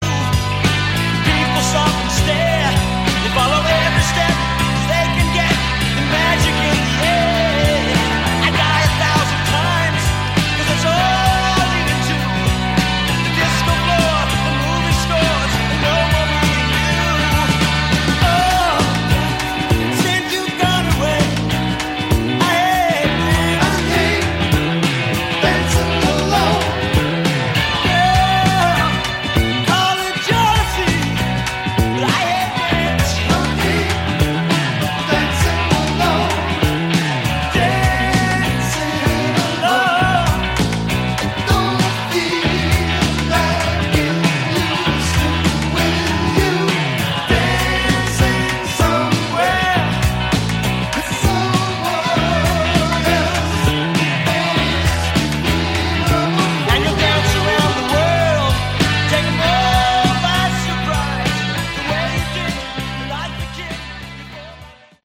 Category: Classic Hard Rock